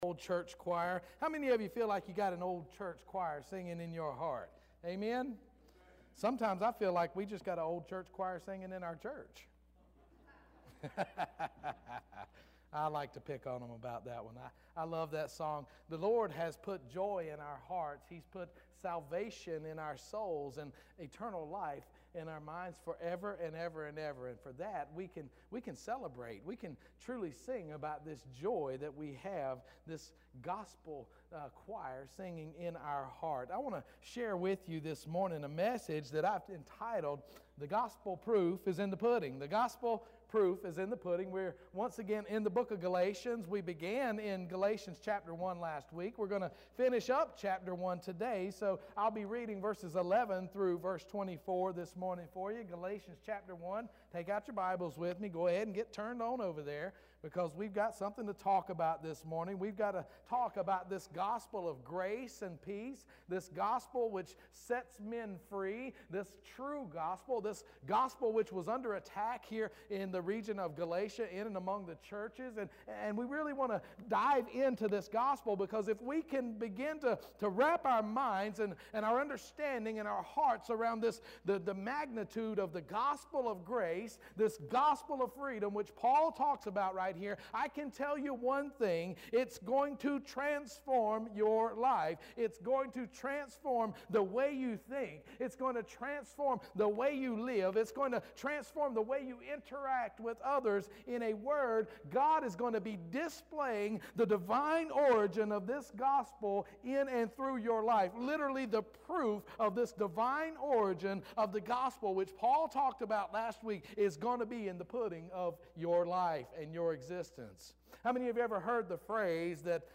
First Baptist Church Roanoke Rapids Online Sermons